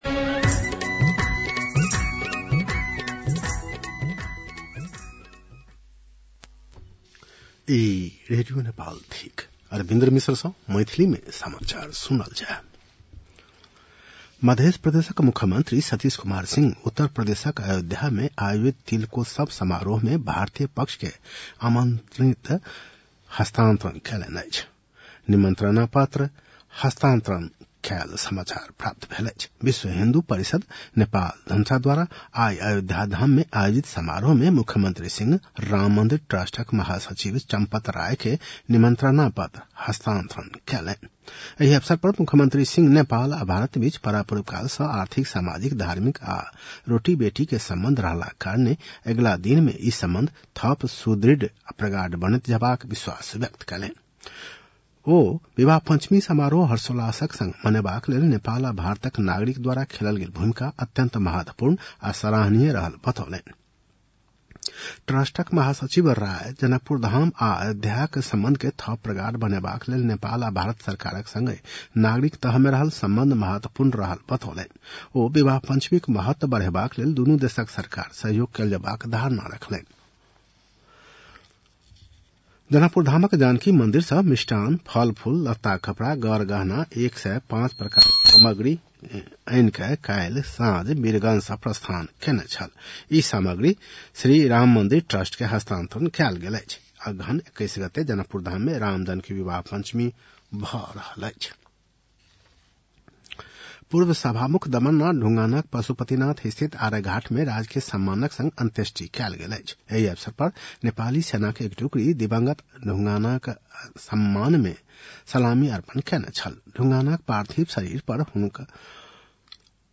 मैथिली भाषामा समाचार : ४ मंसिर , २०८१
Maithali-news-8-02.mp3